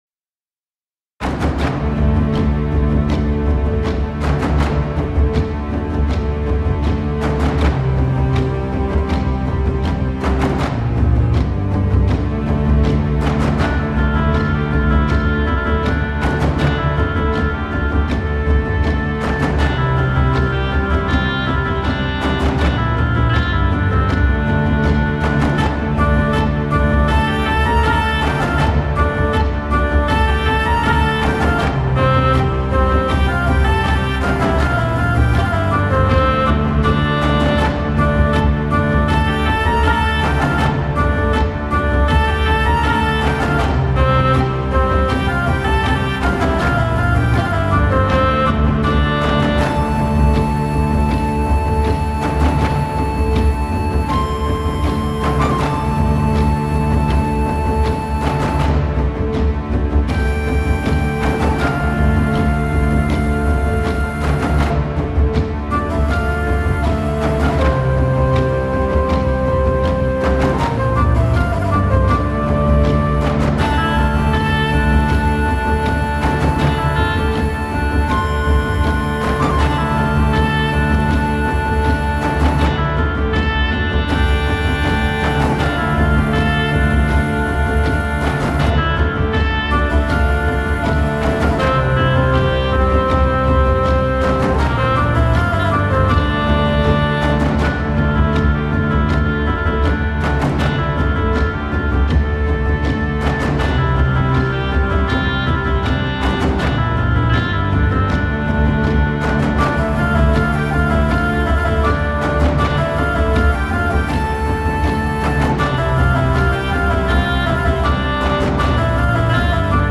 Celtic-Music-Warrior.mp3